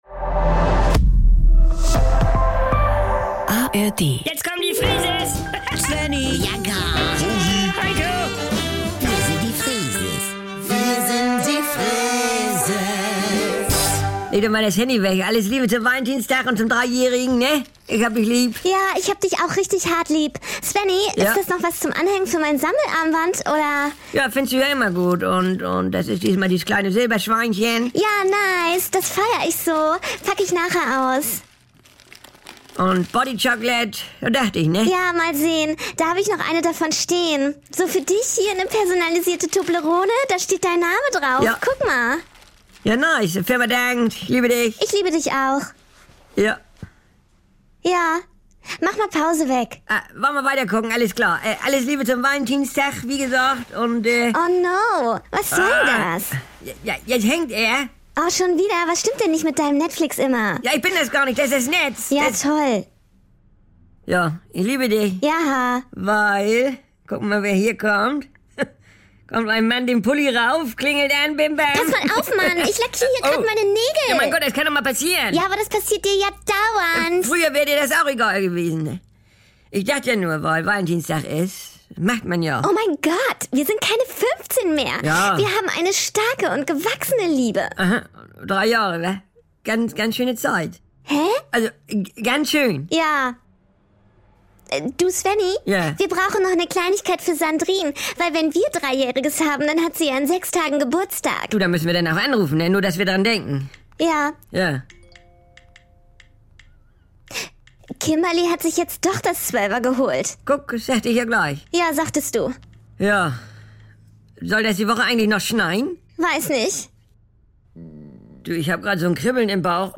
Jederzeit und so oft ihr wollt: Die NDR 2 Kult-Comedy direkt aus dem Mehrgenerationen-Haushalt der Familie Freese.